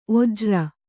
♪ ojra